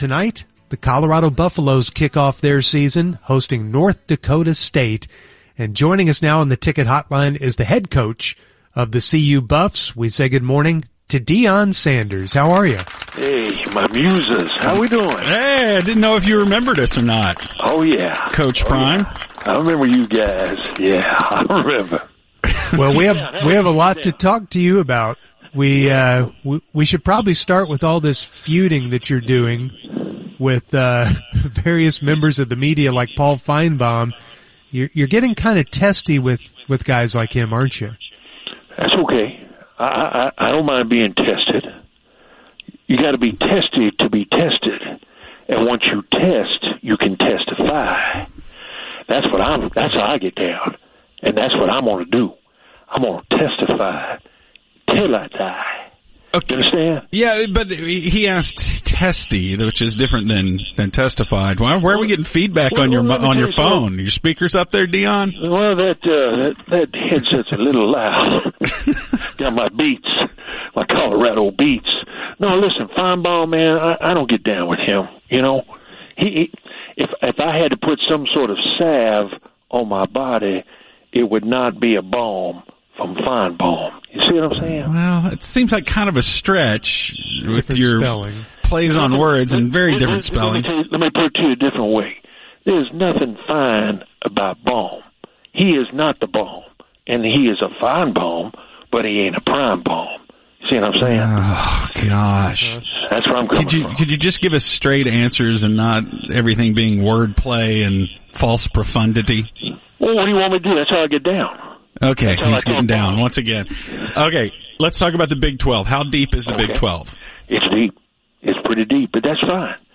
Fake Deion Sanders – The Muses 8.29.2024